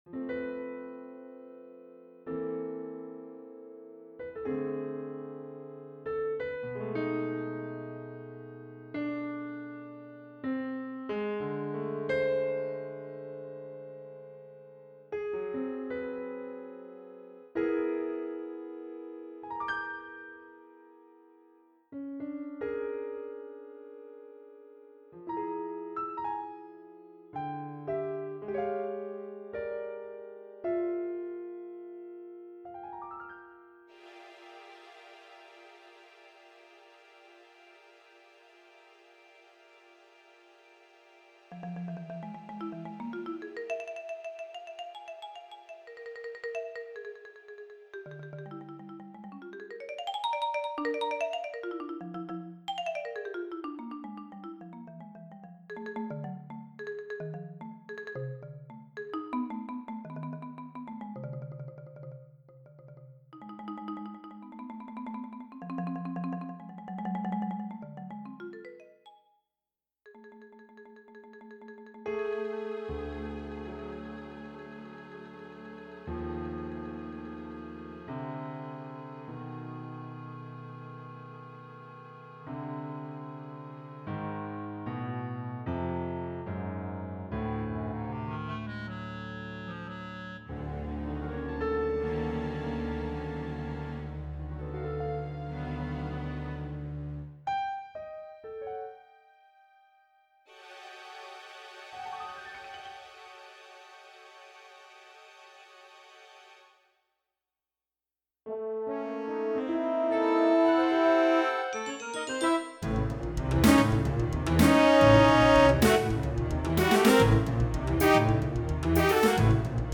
Many instruments are called on for solos, (possibly extended–especially piano and marimba) which places this work somewhere between a chamber symphony, orchestral work, and a concerto. It was designed to stand with or without a large string section.
Concerto for Piano, Percussion, and Orchestra, mvt. 4, mp3, 5' 55" (demo)